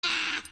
Download Evil Minion Bahhh (cut) sound button
bahhh_5cfvk7c.mp3